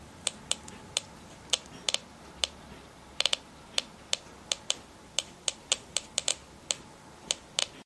Mit diesem Messgerät kann radioaktive Strahlung festgestellt werden. In der Nähe einer radioaktiven Substanz hörst du ein unregelmäßiges Klicken (
Geigerzähler
Geiger_counter.ogg